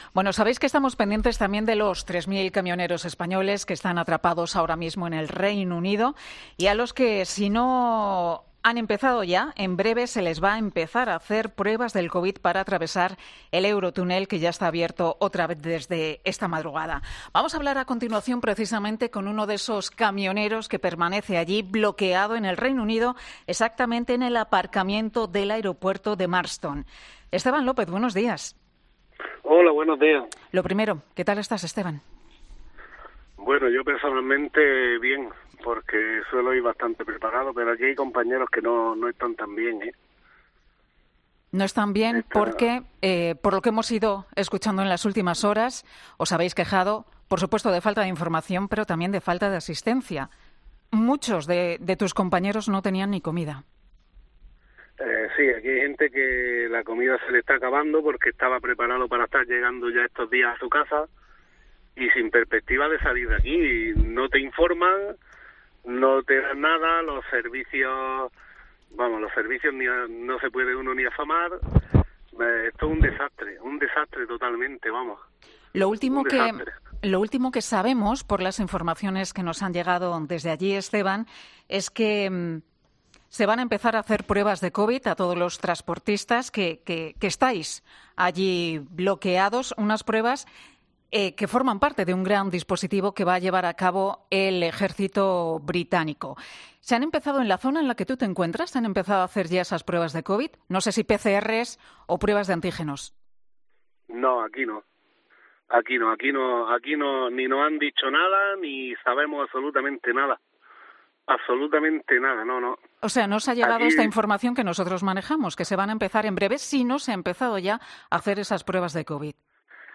uno de los c amioneros atrapados que se encuentra bloqueado en Dover